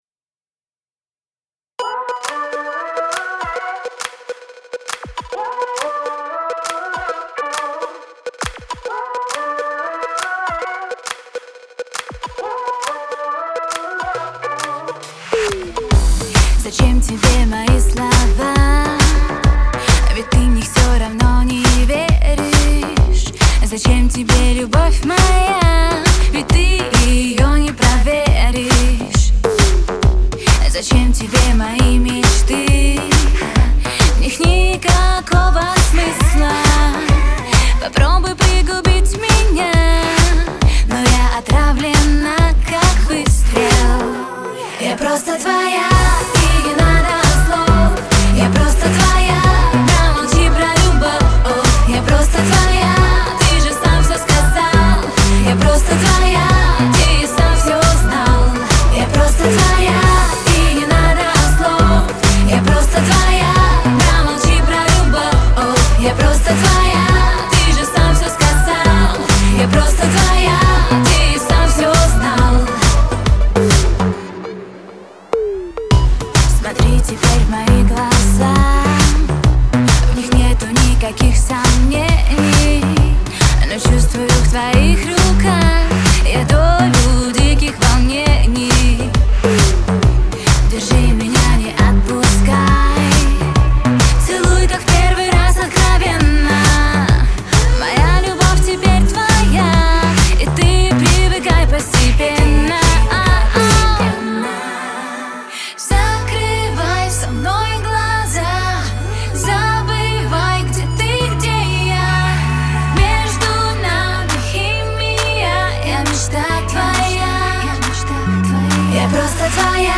Категория: Популярная музыка